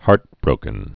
(härtbrōkən)